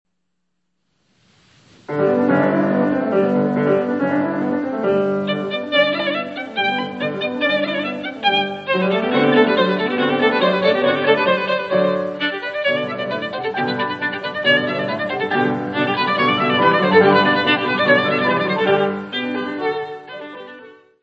: mono; 12 cm
Music Category/Genre:  Classical Music
Allegro vivace.